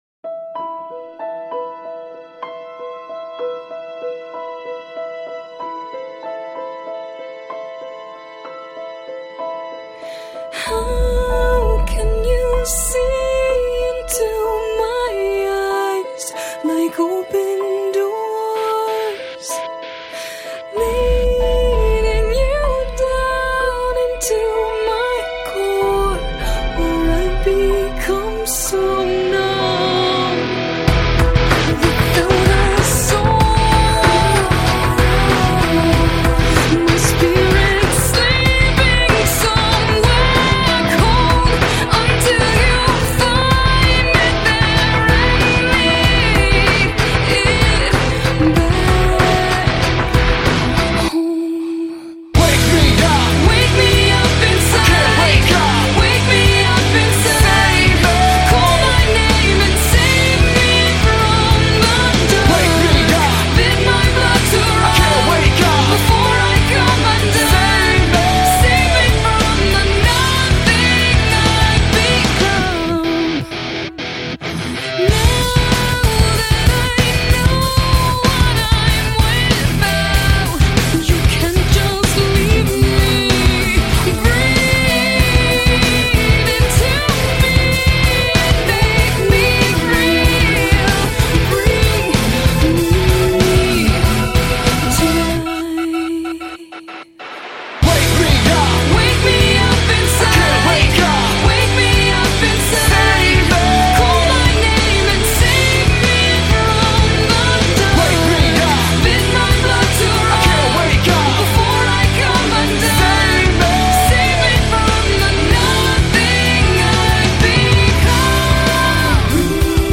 Жанр: Alternative, Gothic Metal